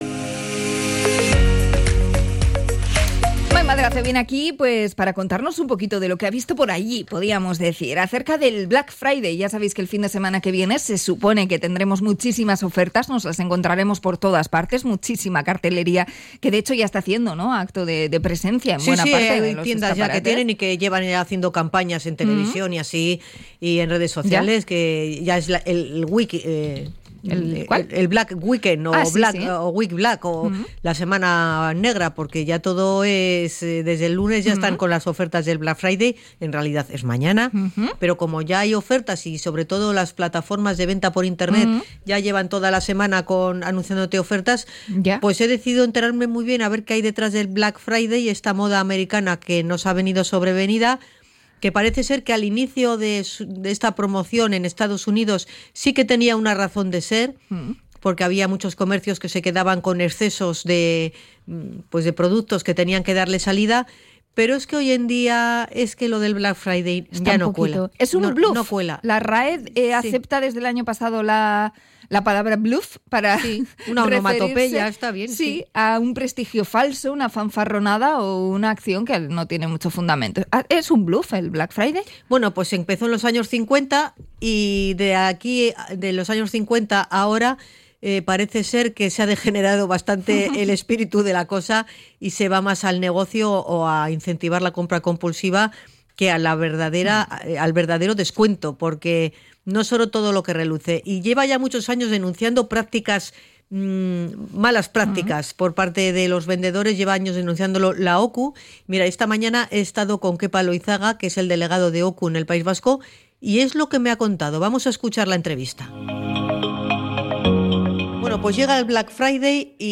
Hablamos con la OCU y con paseantes por las calles más comerciales de Bilbao
Salimos a la calle en Bilbao para comprobar que los encuestados no se fían de la "trampa" de los precios